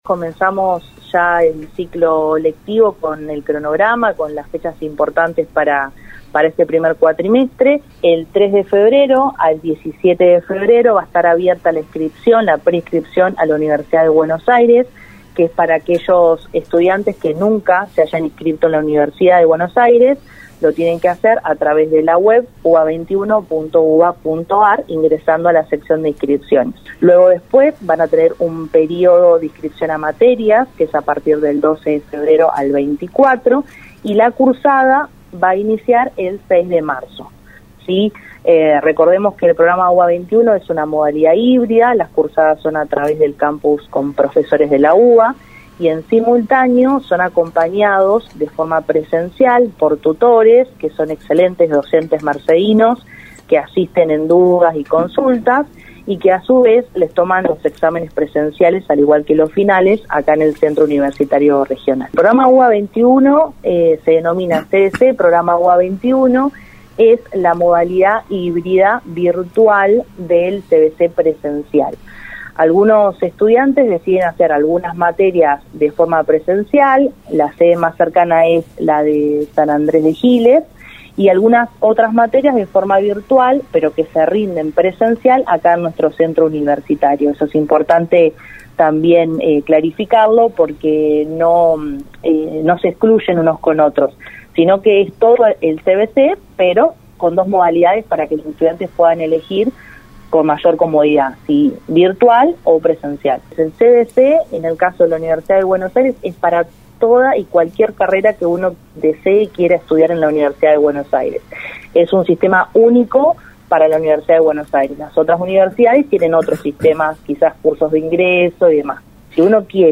en Radio Universo